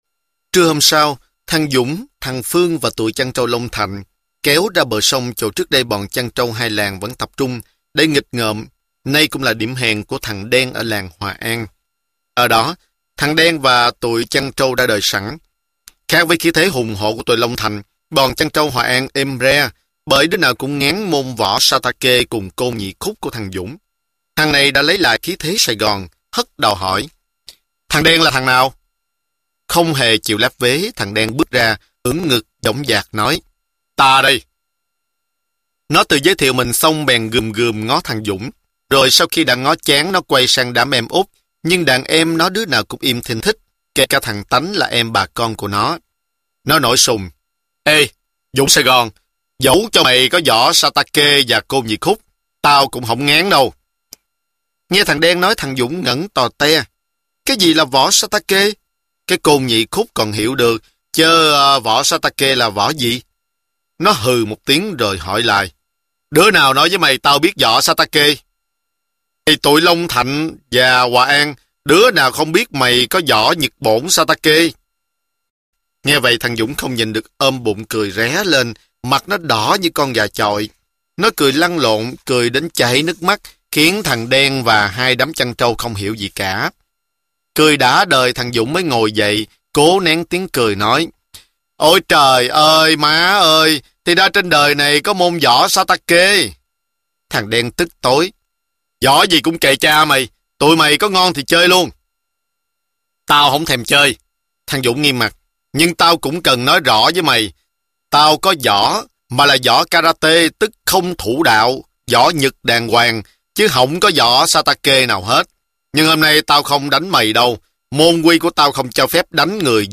Sách nói Dũng Sài Gòn - Nguyễn Trí Công - Sách Nói Online Hay